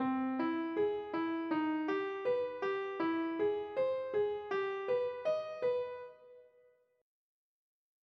This third example is the most popular augmented scale pattern. It makes use of the three triads a major third apart.
playing augmented patterns